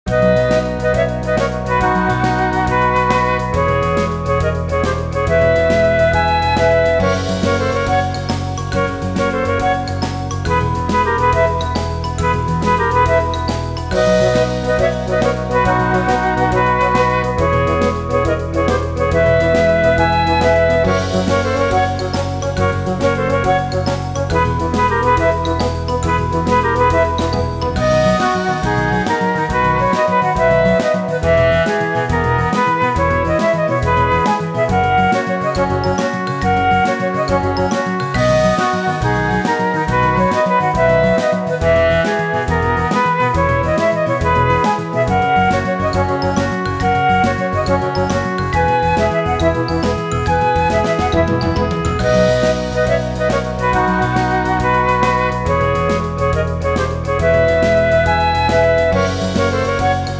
イメージ：さわやか 軽快   カテゴリ：RPG−外・ダンジョン